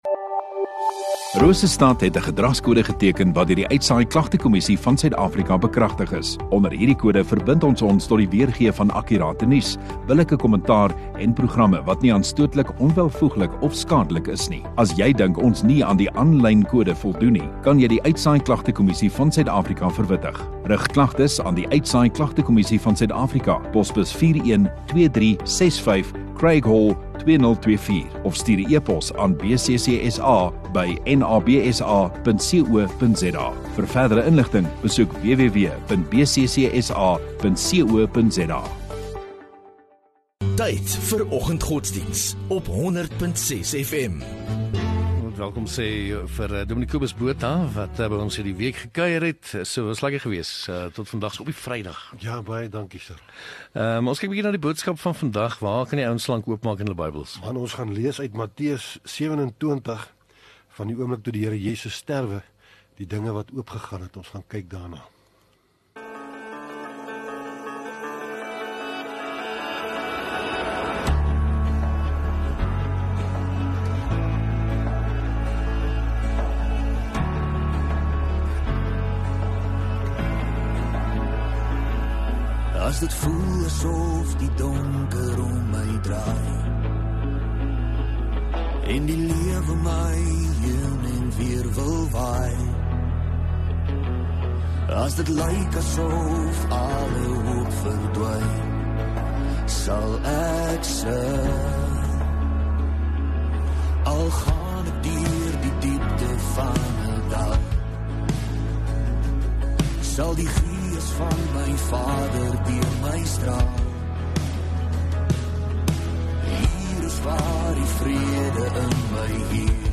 19 Apr Vrydag Oggendddiens